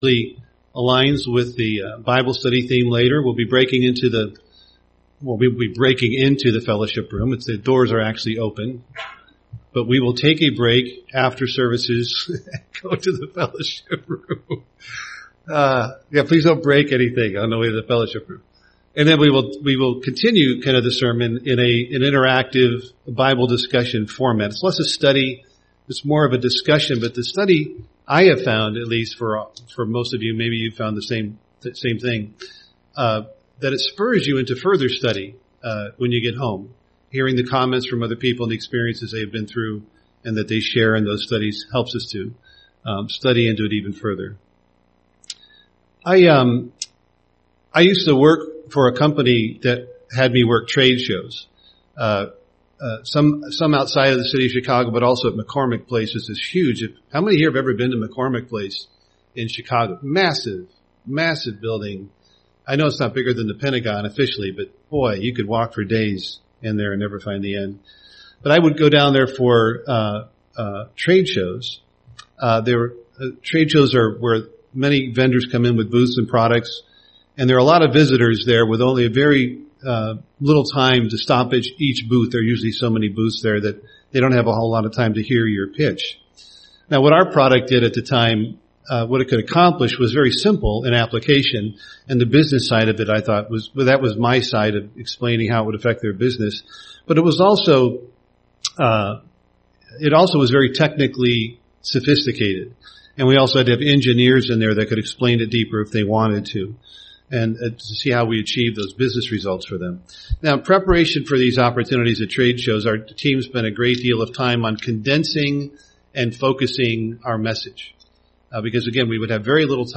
UCG Sermon Gospel gospel message Studying the bible?